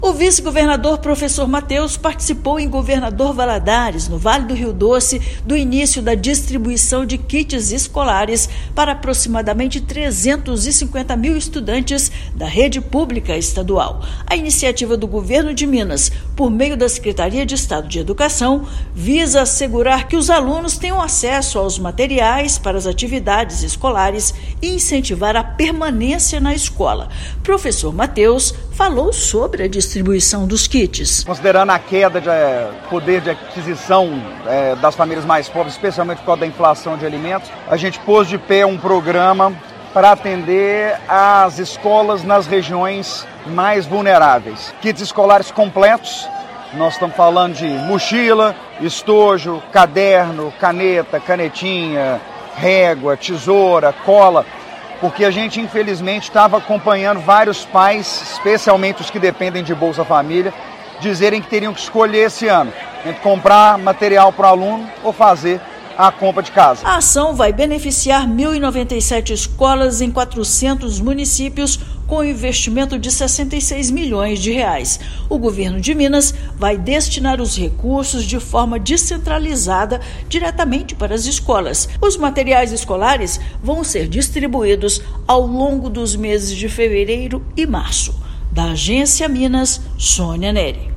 Objetivo é garantir igualdade de oportunidades, promover o desenvolvimento educacional, incentivando a permanência na escola. Ouça matéria de rádio.